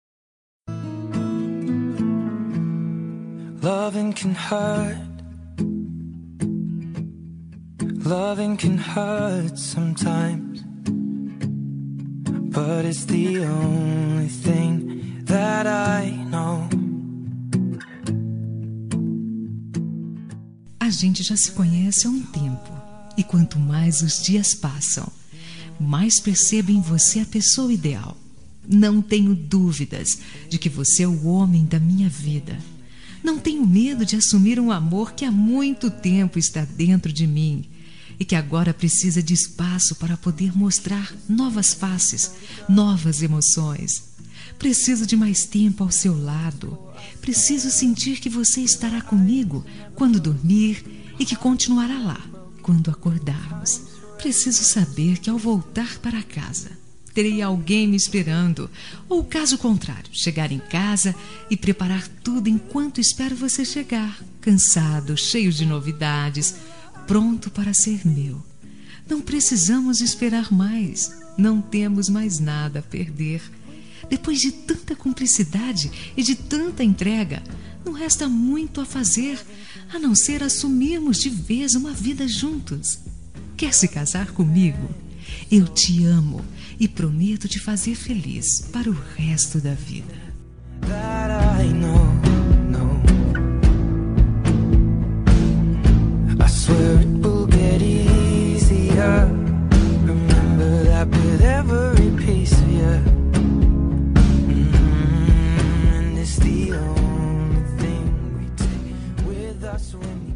Telemensagem de Pedido – Voz Feminina – Cód: 20183 – Quer Casar
10000-casamento-fem.m4a